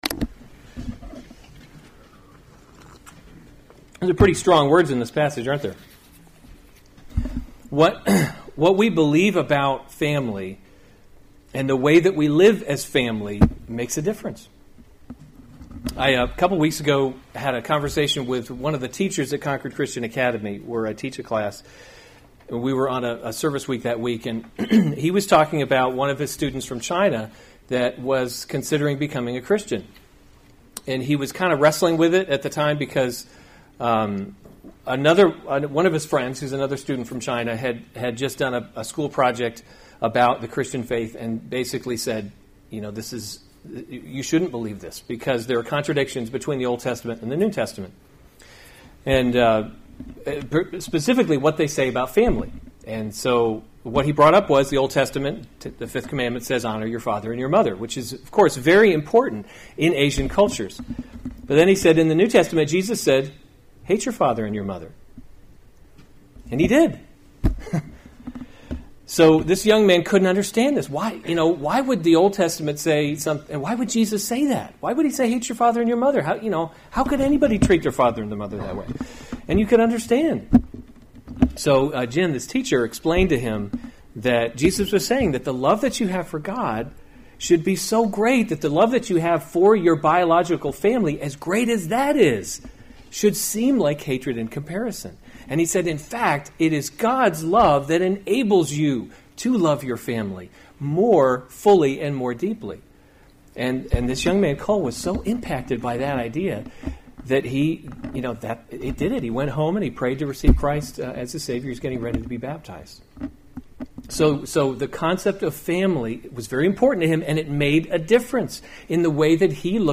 May 6, 2017 1 Timothy – Leading by Example series Weekly Sunday Service Save/Download this sermon 1 Timothy 5:1-8 Other sermons from 1 Timothy Instructions for the Church 5:1 Do not […]